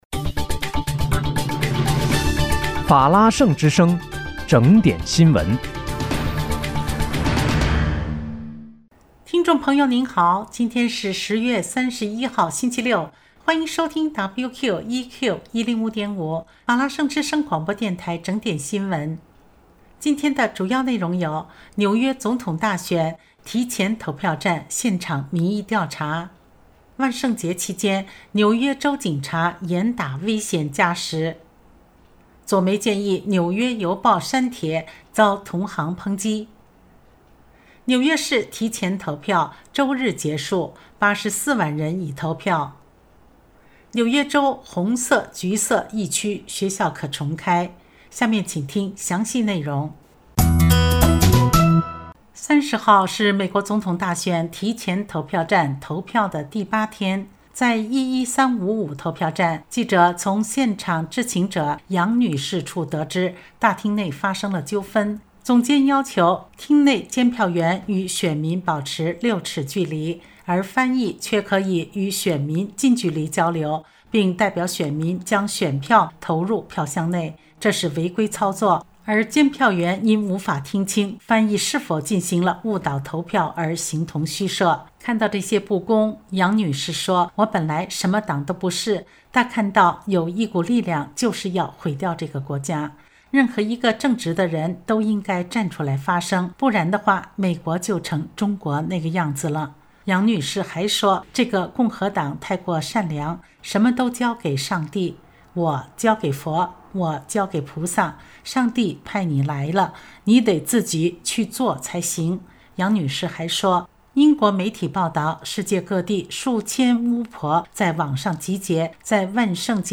10月31日（星期六）纽约整点新闻